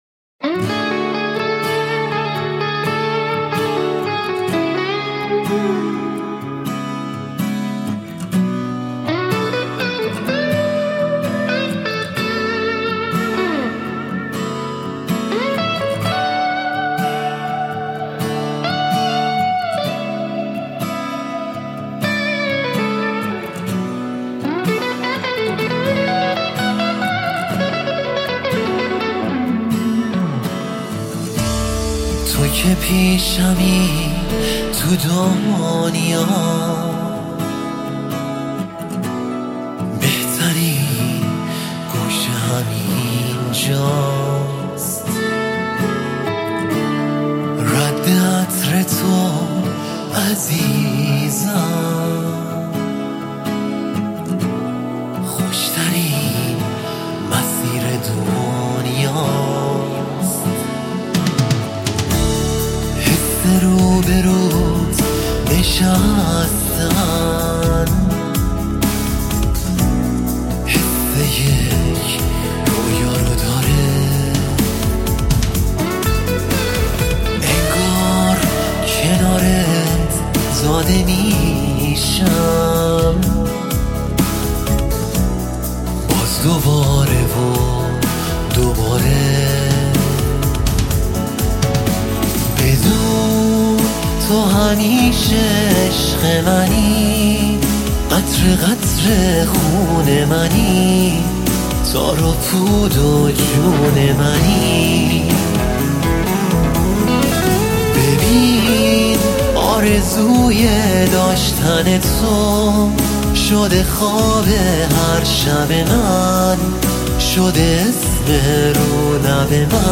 موسیقی پاپ، راک و جز